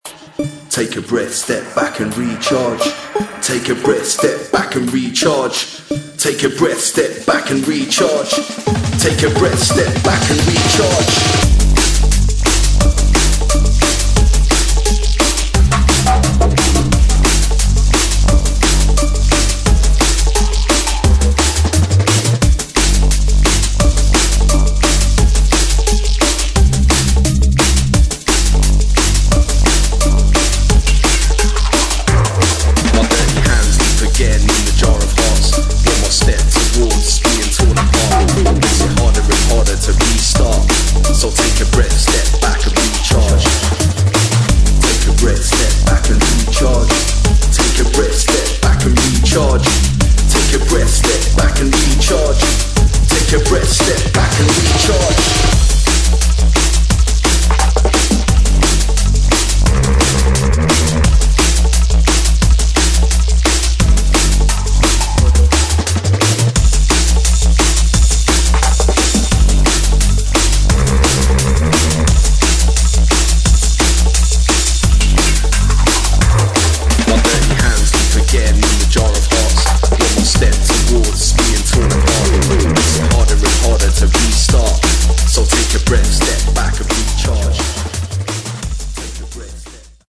[ DRUM'N'BASS / JUNGLE ]